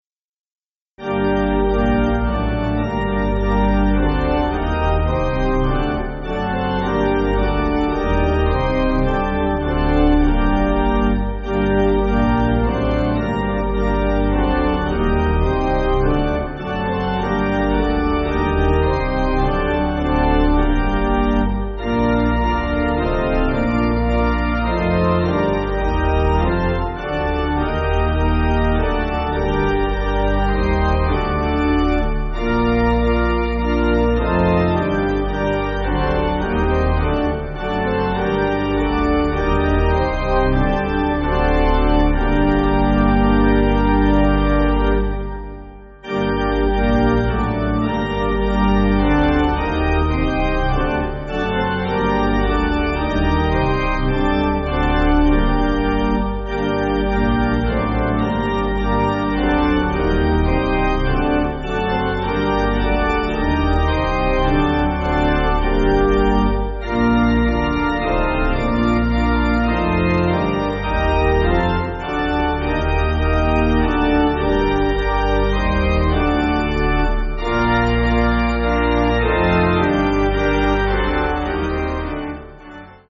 Organ
(CM)   2/Gm